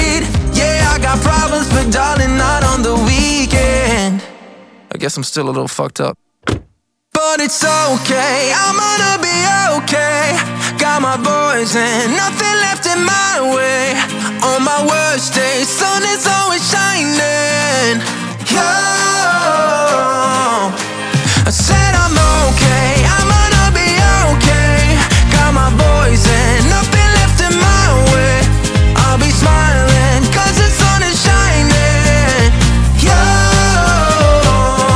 2025-06-11 Жанр: Поп музыка Длительность